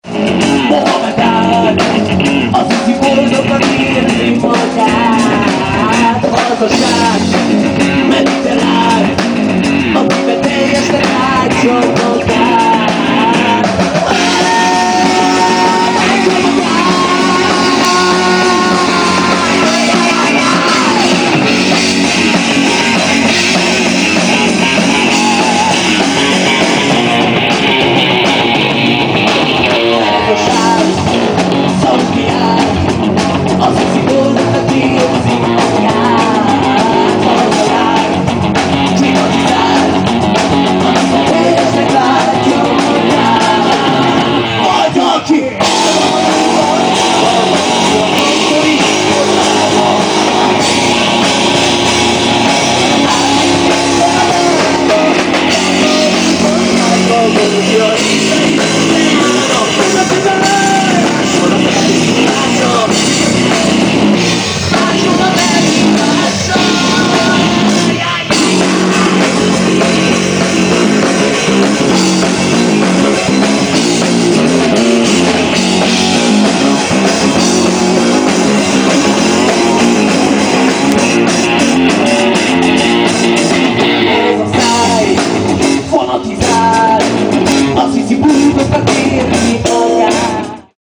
Néhány koncertfelvétel: